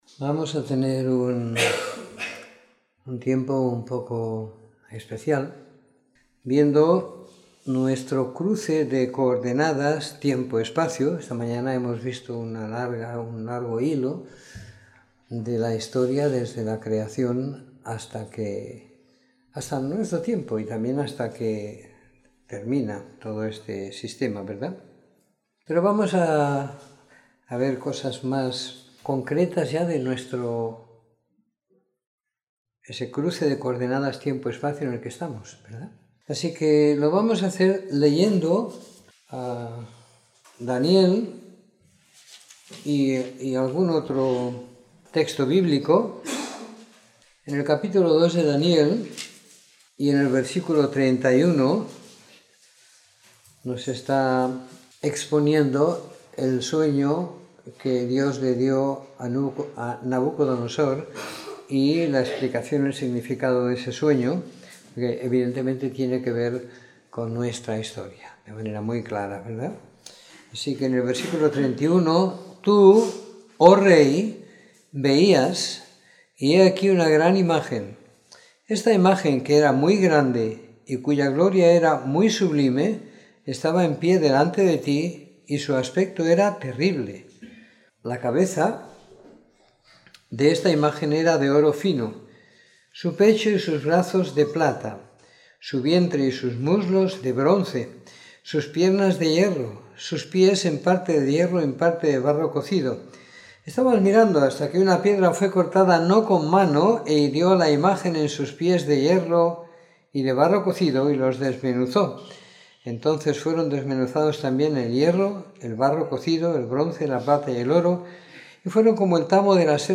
Domingo por la Tarde . 22 de Octubre de 2017